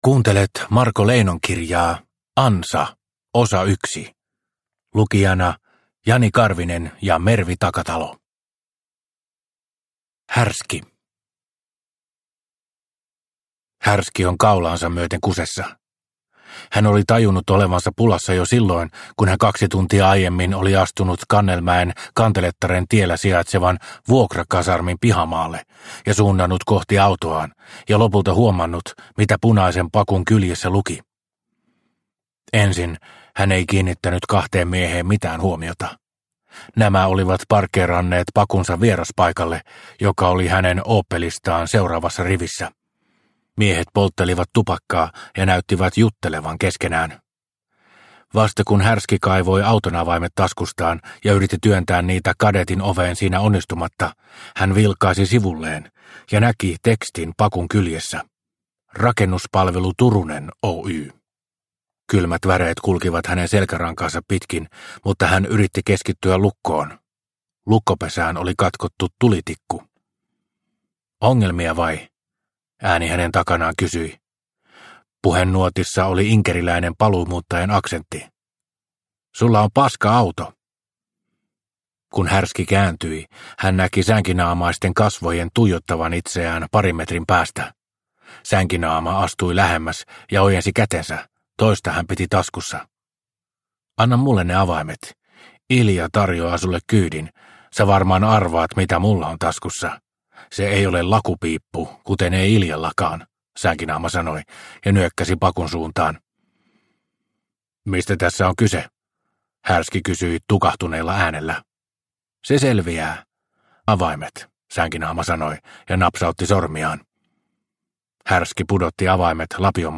Ansa – Ljudbok – Laddas ner